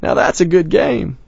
gutterball-3/Gutterball 3/Commentators/Bill/b_thatsagoodgame.wav at 58b02fa2507e2148bfc533fad7df1f1630ef9d9b
b_thatsagoodgame.wav